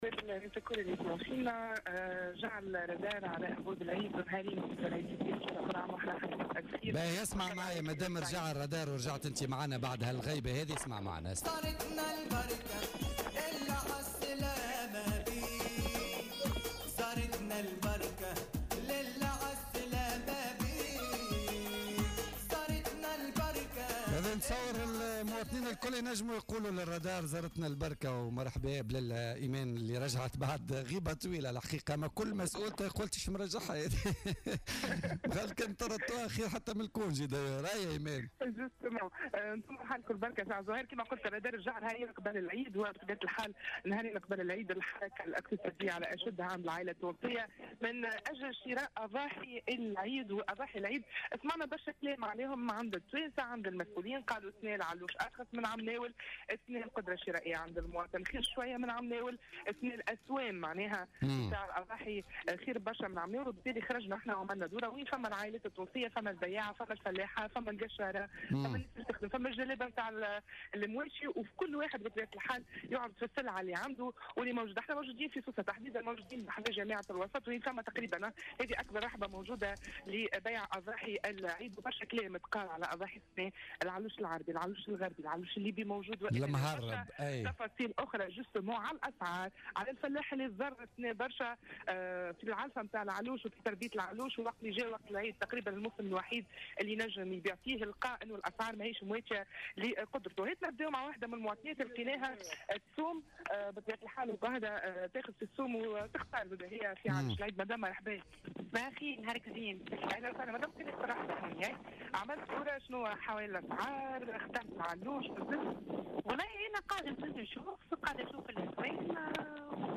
تنقل الرادار صباح اليوم الأربعاء إلى سوق بيع الأضاحي قرب جامعة الوسط بسوسة لمعاينة مدى اقبال العائلات التونسية على شراء الأضاحي يومان فقط على حلول عيد الأضحى.